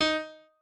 pianoadrib1_14.ogg